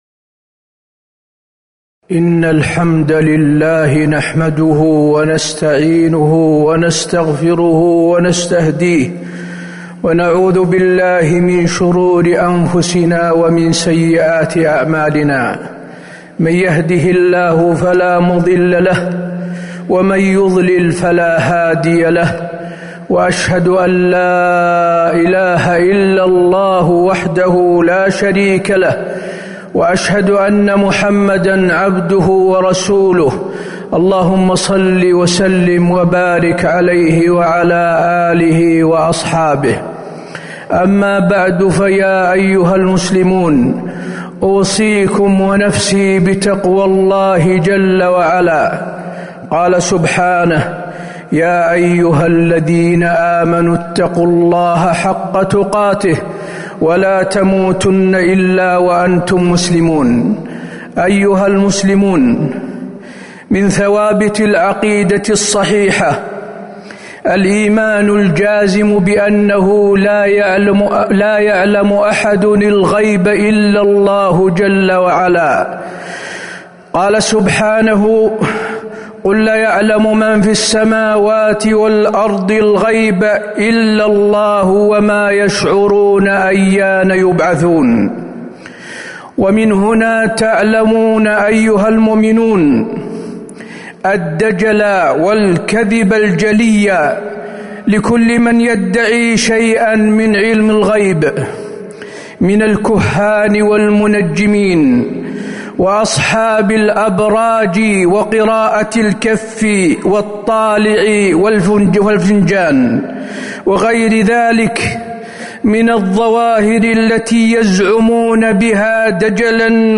تاريخ النشر ٢٤ ربيع الثاني ١٤٤٤ هـ المكان: المسجد النبوي الشيخ: فضيلة الشيخ د. حسين بن عبدالعزيز آل الشيخ فضيلة الشيخ د. حسين بن عبدالعزيز آل الشيخ التحذير من الكهان والمنجمين